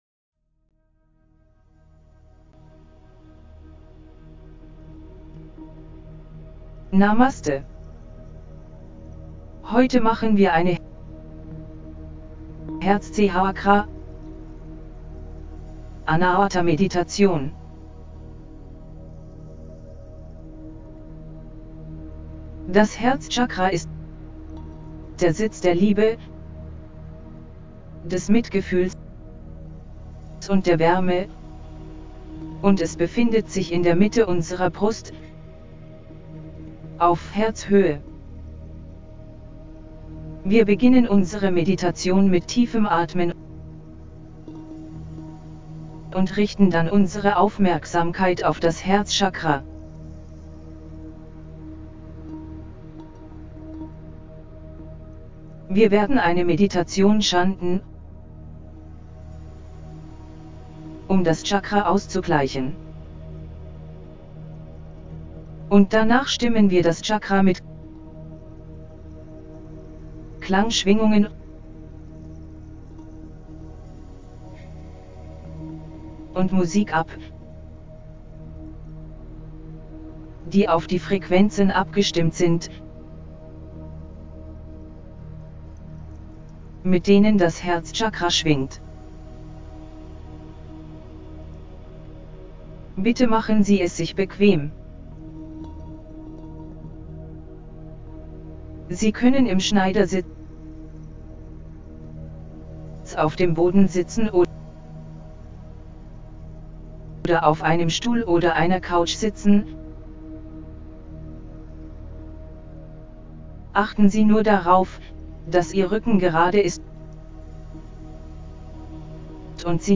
4HeartChakraHealingGuidedMeditationDE.mp3